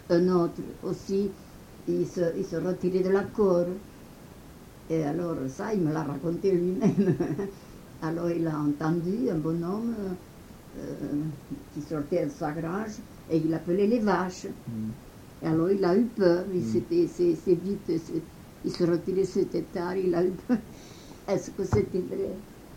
Aire culturelle : Couserans
Genre : conte-légende-récit
Type de voix : voix de femme
Production du son : parlé
Classification : récit de peur